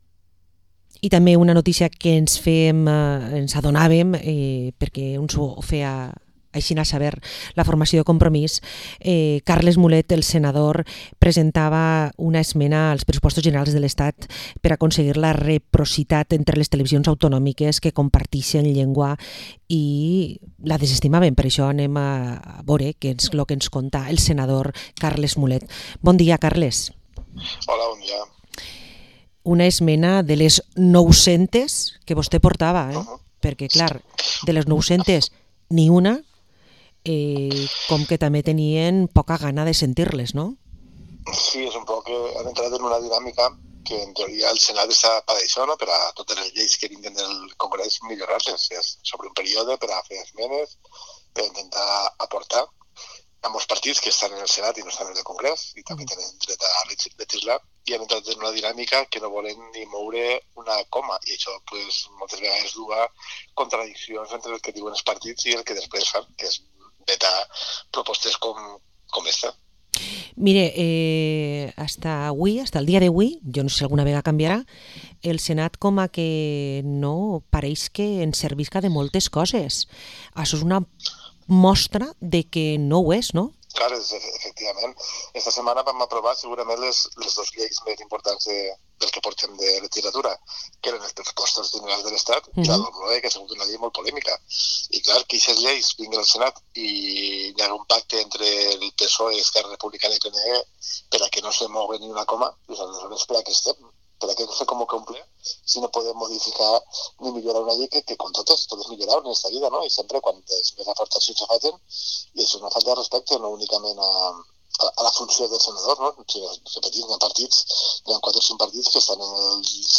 Entrevista al Senador de Compromís Carles Mulet